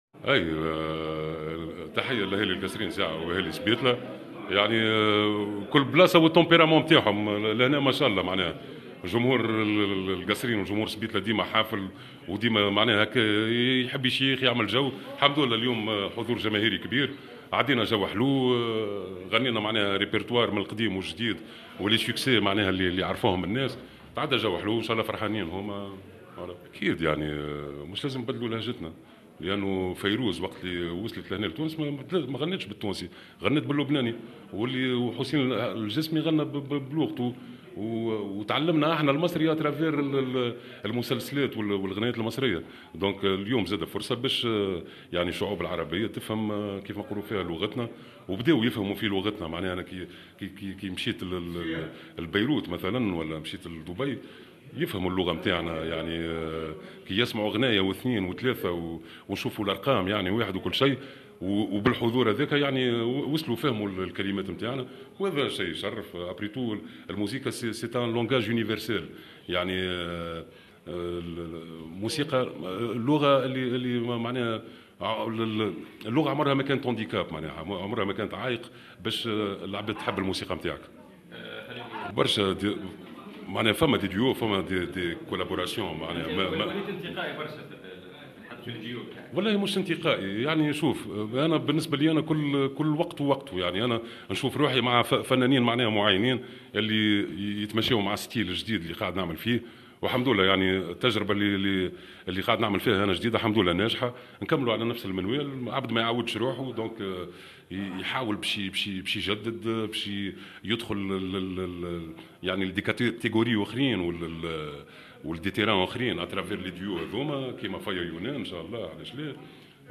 Dans une déclaration faite, jeudi 3 août2023 à Tunisie Numérique, Balti a estimé que ” l’art a permis aux différentes nationalités de comprendre notre dialecte tunisien (…) Nous ne changeons pas notre dialecte, c’est une fierté pour nous(…)” a-t-il dit.